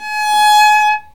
Index of /90_sSampleCDs/Roland L-CD702/VOL-1/STR_Viola Solo/STR_Vla3 _ marc
STR VIOLA 0G.wav